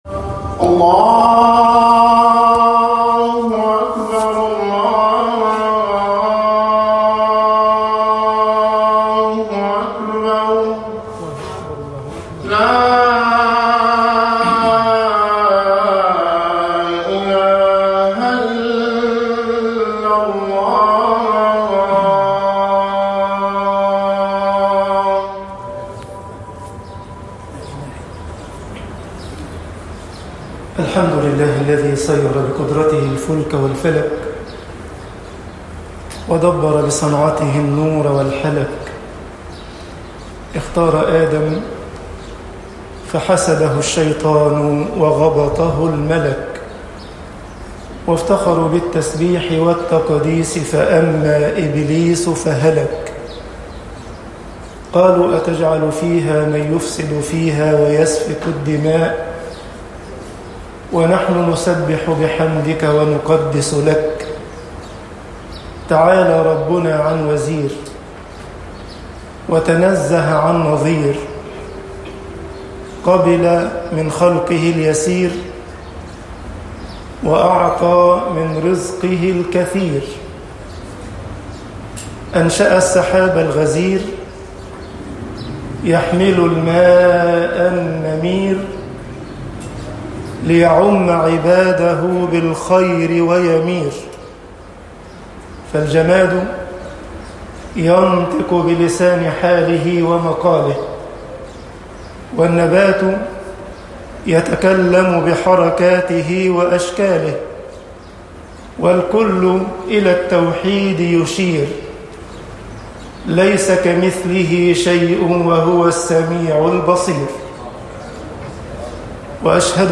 خطب الجمعة - مصر القلب بين الإستقامة والزيغ طباعة البريد الإلكتروني التفاصيل كتب بواسطة